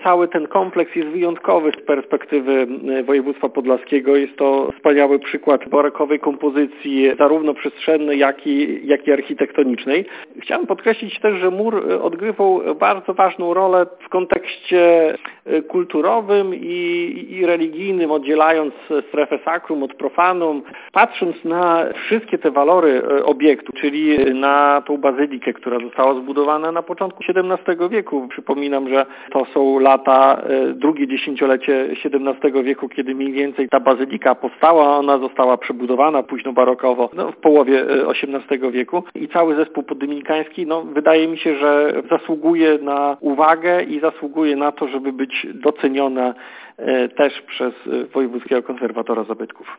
Jak podkreśla Karol Łopatecki, zastępca Podlaskiego Wojewódzkiego Konserwatora Zabytków, świątynia ma wyjątkową wartość historyczną i architektoniczną.